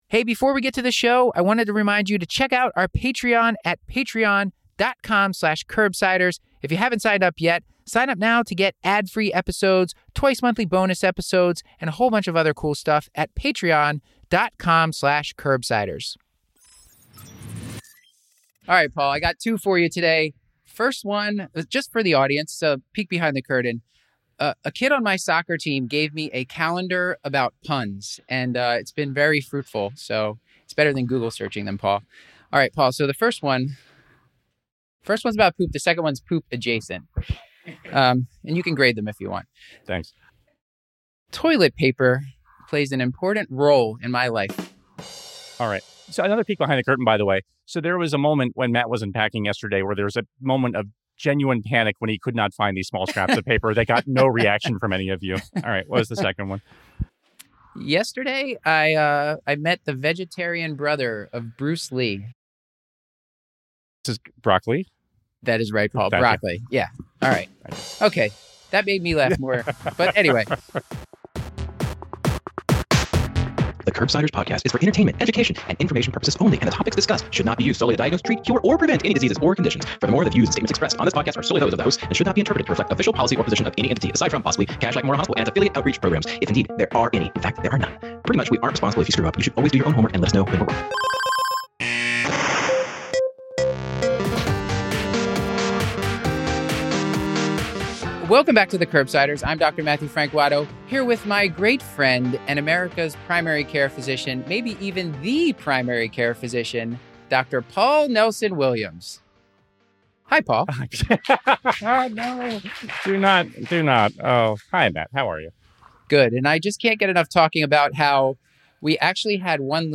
Live from Johns Hopkins Grand Rounds
47:00 Audience Q&A